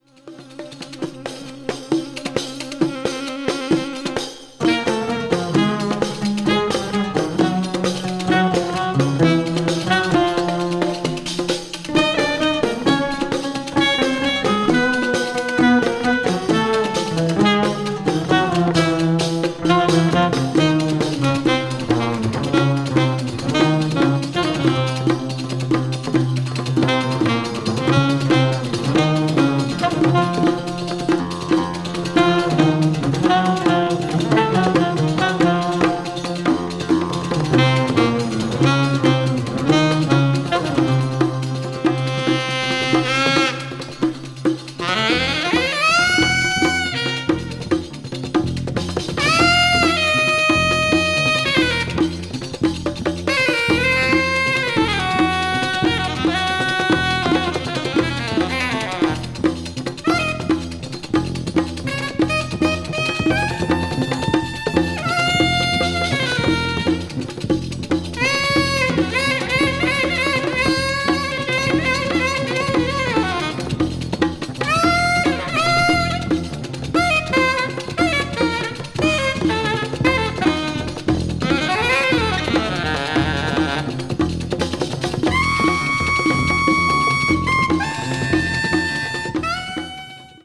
media : EX/EX(わずかにチリノイズが入る箇所あり)
乾燥した大地に舞う土埃のようなザラっとした感触が音を通して伝わってきます。